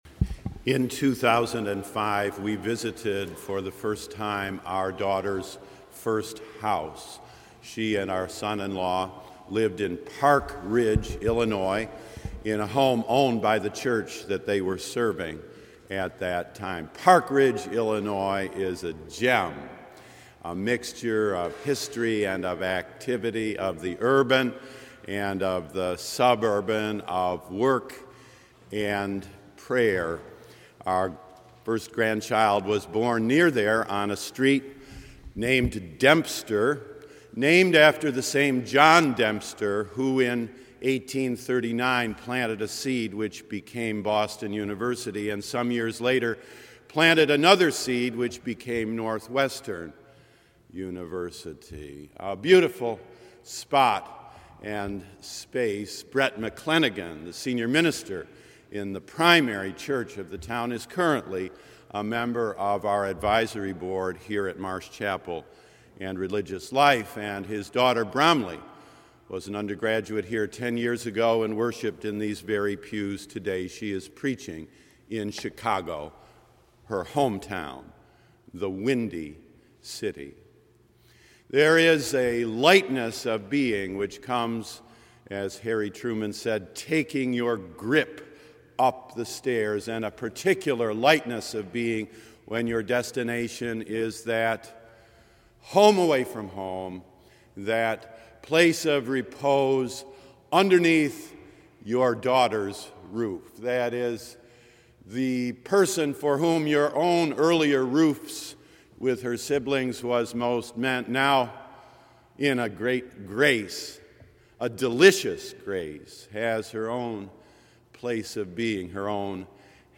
Click here to hear the sermon only. John 1: 6-8, 19-28